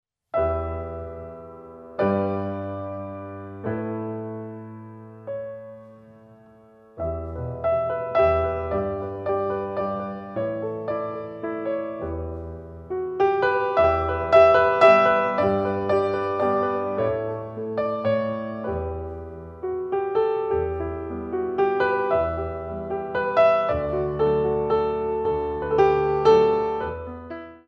Port De Bras